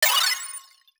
Bubbly Game Achievement Sound.wav